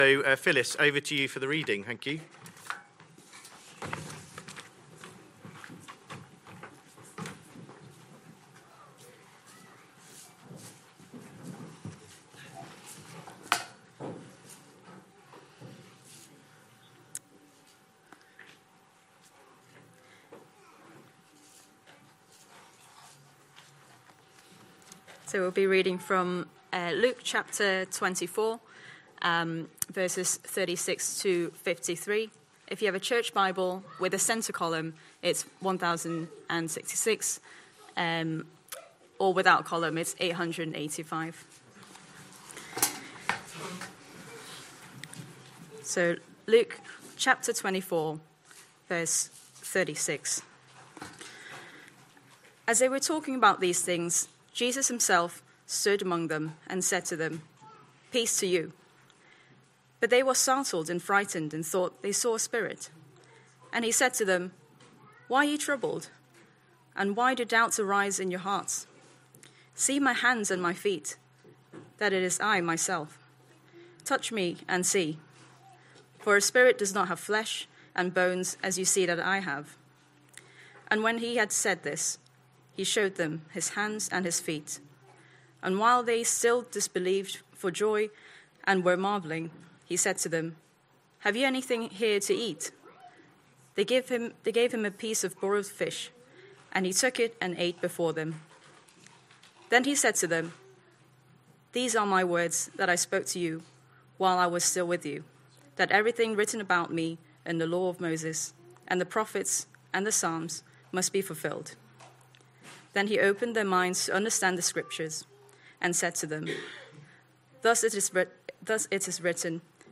Sunday AM Service Sunday 2nd November 2025 Speaker